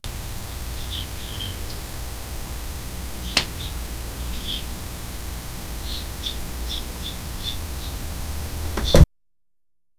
Quiz questions curiosity sound
quiz-questions-curiosity--ilbaz2gh.wav